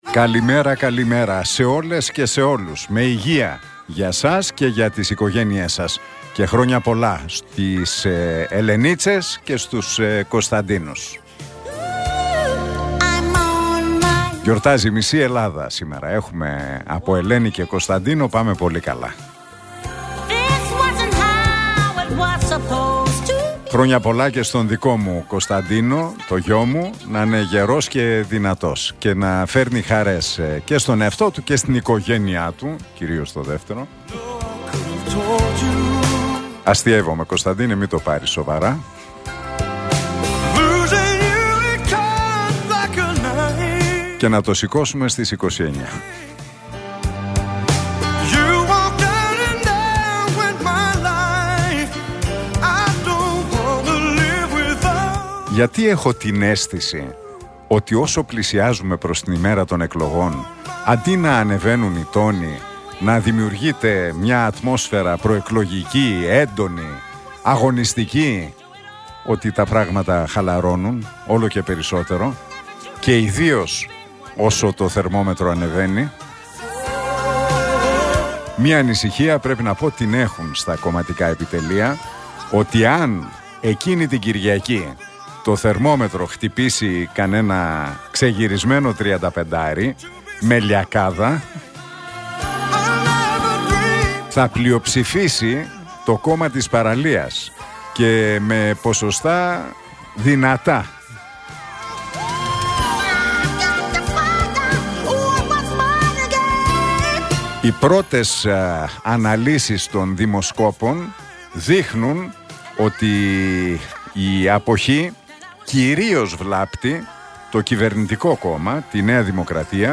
Ακούστε το σχόλιο του Νίκου Χατζηνικολάου στον RealFm 97,8, την Τρίτη 21 Μαΐου 2024.